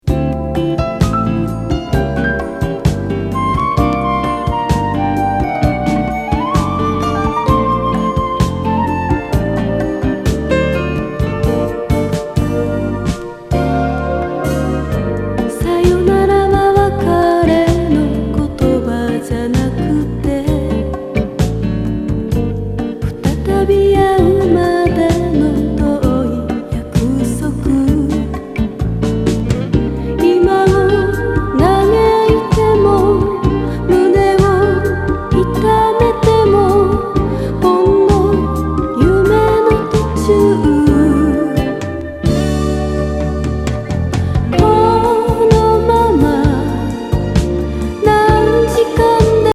程よいエコー処理でイイ